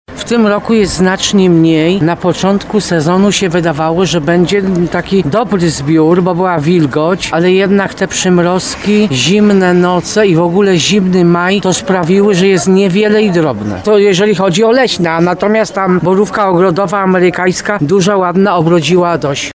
mówi jedna ze sprzedawczyń